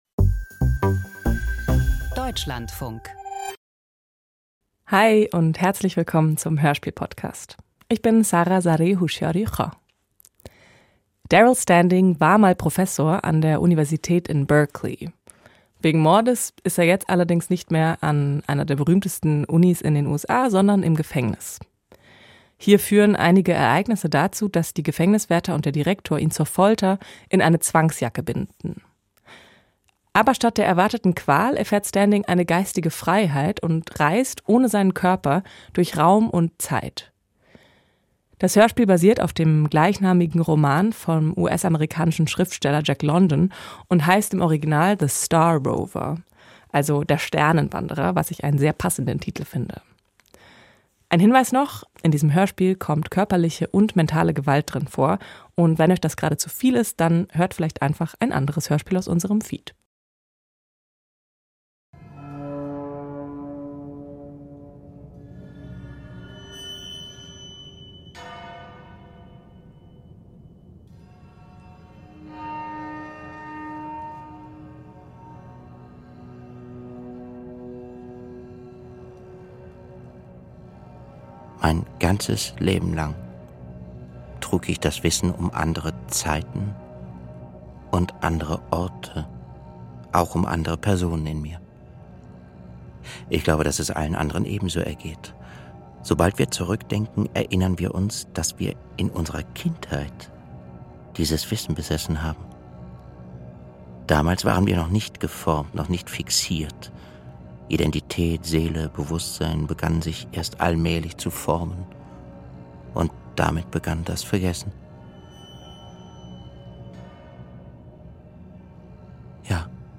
Die Zwangsjacke - Gefängnisdrama nach Jack London ~ Hörspiel Podcast